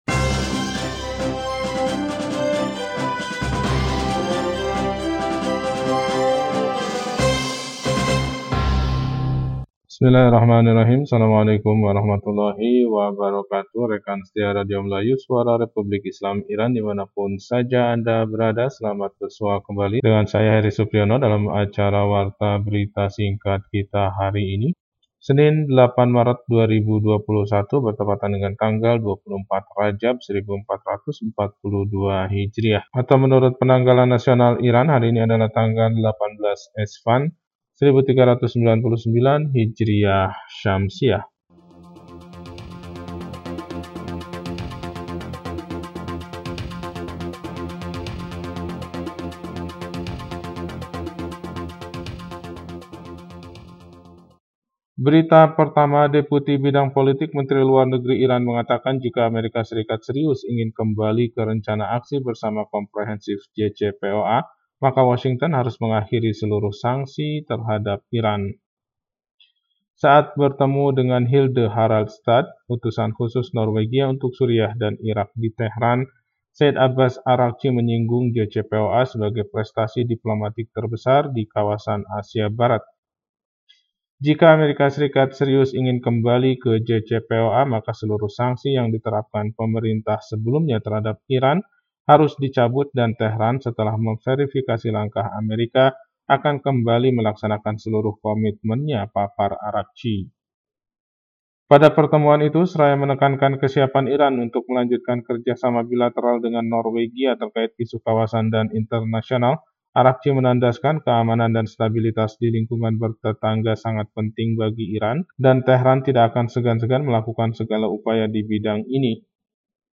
Warta Berita 8 Maret 2021